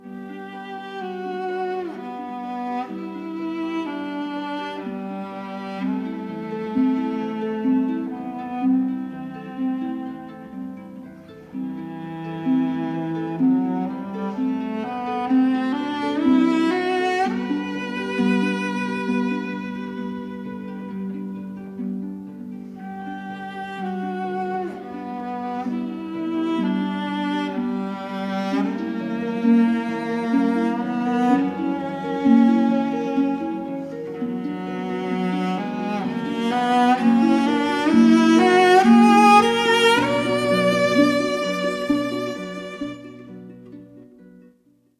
Jacqueline-du-Pre-Saint-Saens-The-swan.mp3